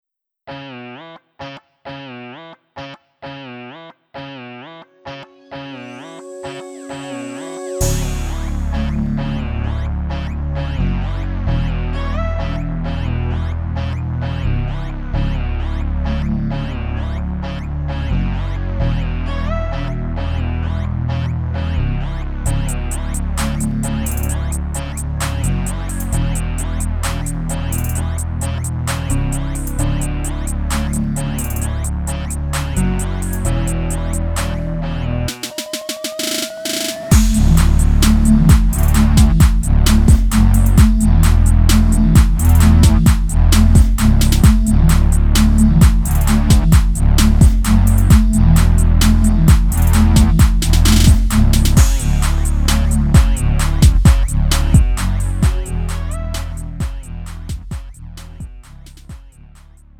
음정 -1키 3:15
장르 가요 구분 Lite MR